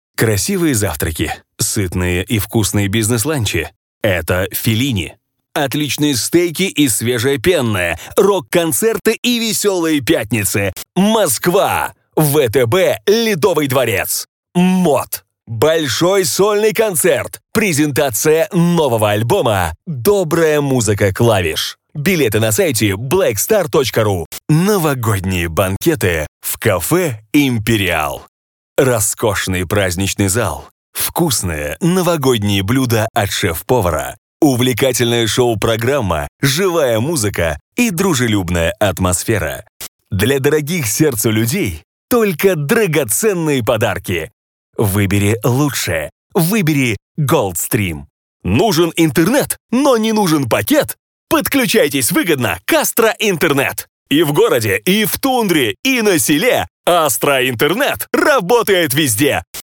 Разноплановый диктор.
Тракт: AKG414, Rode NTK -> WarmerSound MC41, dbx376 -> TC Impact Twin